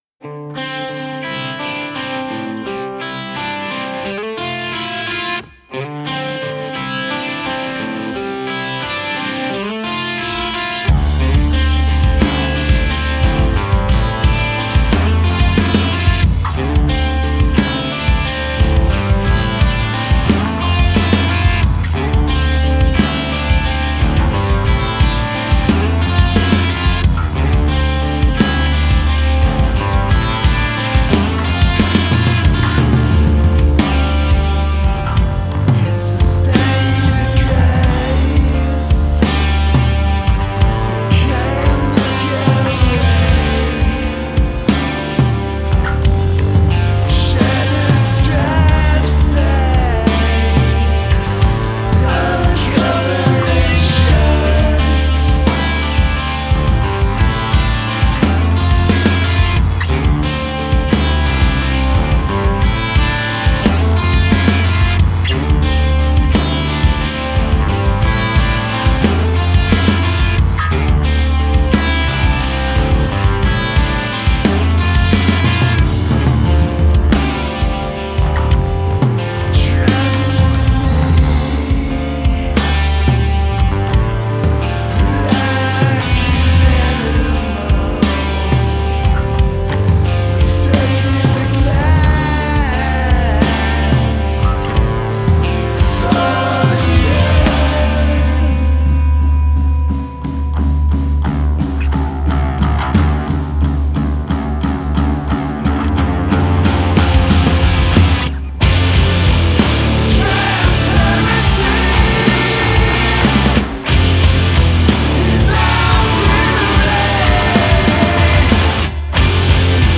Bass
Drums
Guitar / Vocals
Emo , Indie , Rock